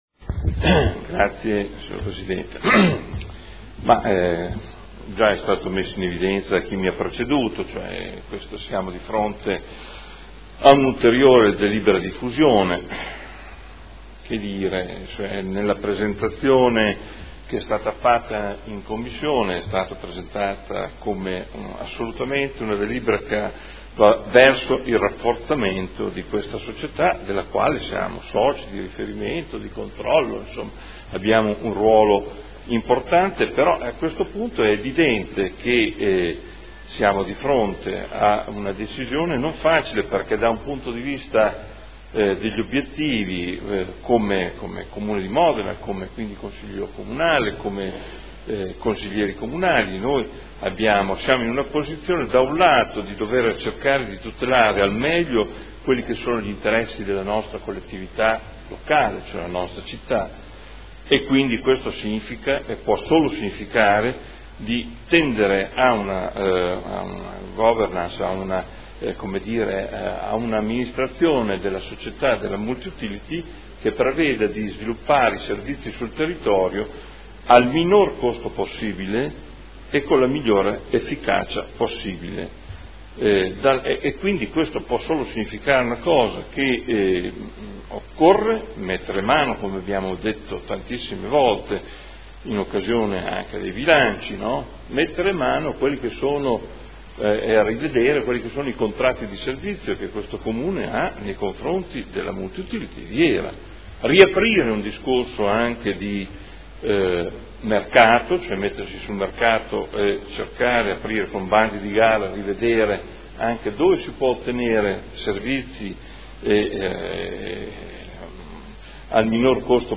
Adolfo Morandi — Sito Audio Consiglio Comunale
Seduta del 24 marzo. Proposta di deliberazione: Fusione per incorporazione di AMGA Azienda Multiservizi di Udine in Hera e modifiche allo Statuto Hera. Dibattito